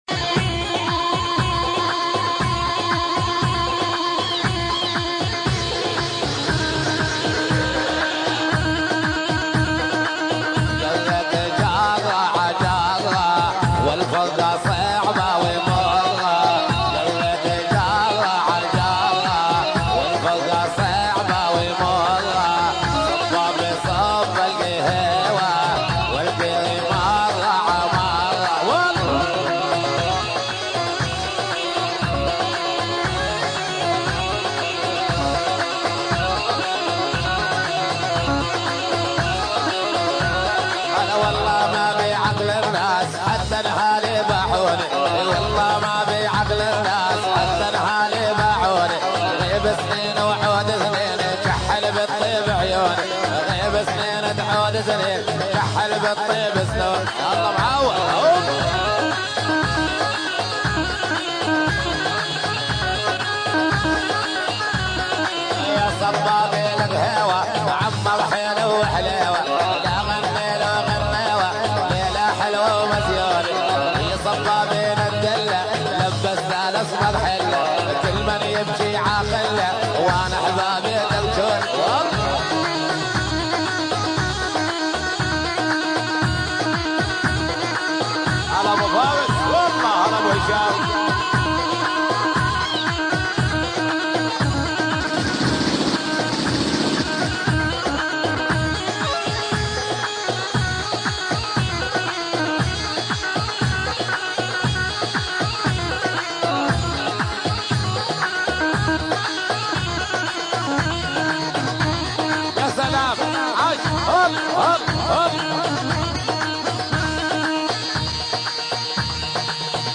أغاني فلسطينيه